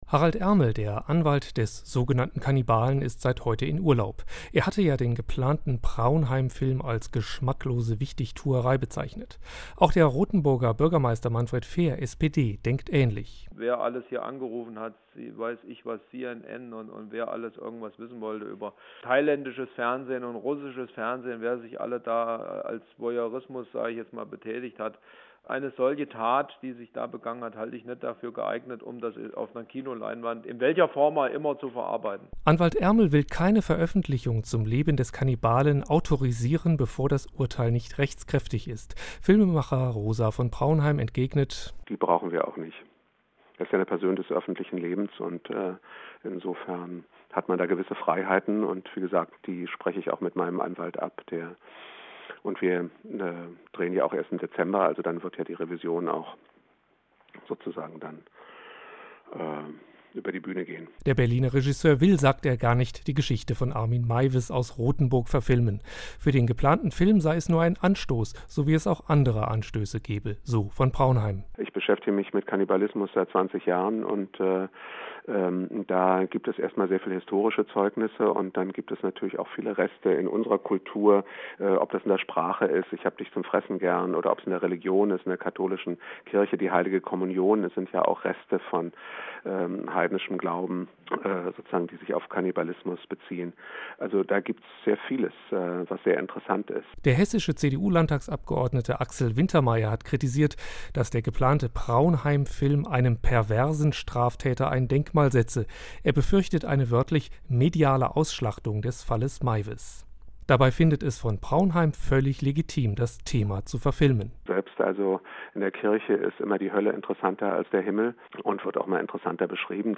Er verteidigte damals, 2004, sein Absicht, den Film zu machen, auch gegenüber mir – ich machte damals einen Radio-Beitrag für den hr über diese Diskussion.